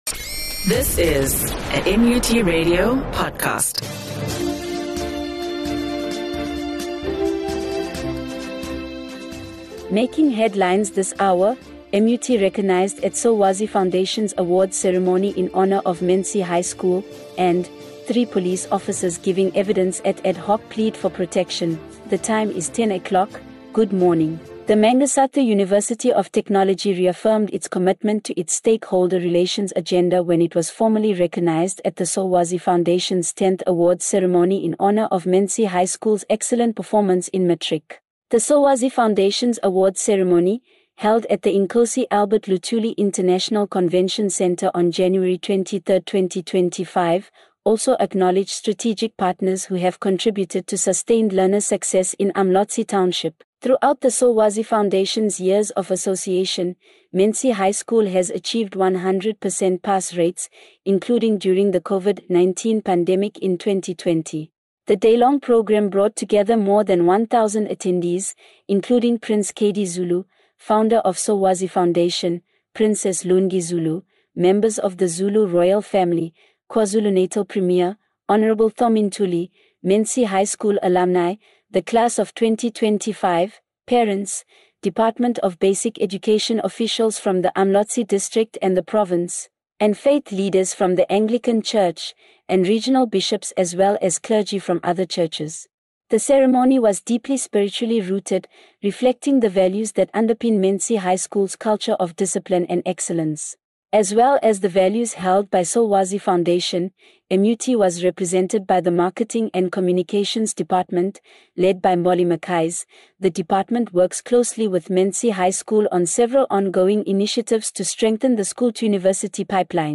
MUT RADIO NEWS AND SPORTS